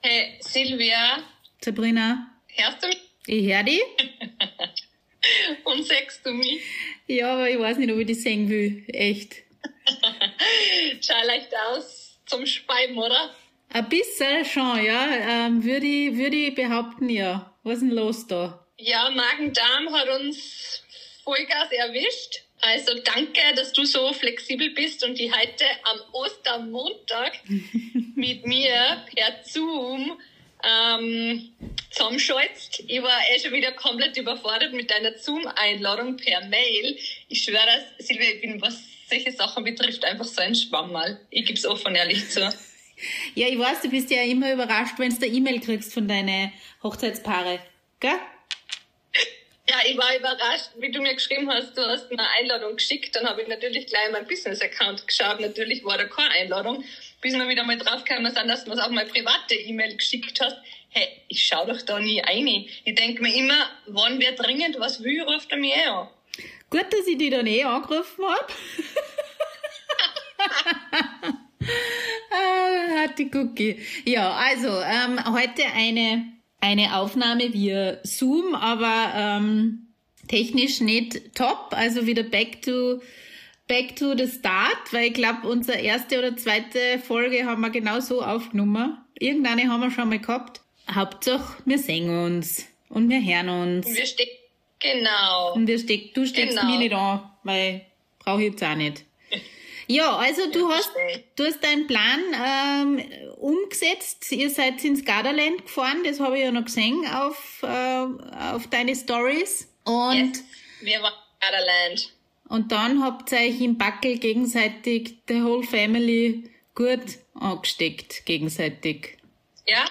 Das erwartet euch: Outdoor-Trauungen: Learnings, Missgeschicke & ehrliche Tipps Gästeliste ohne Drama – so geht's Einblicke in unseren (manchmal chaotischen) Alltag als Mamas & Traurednerinnen P.S. Die Tonqualität ist diesmal etwas abenteuerlich – aber der Inhalt macht's wett!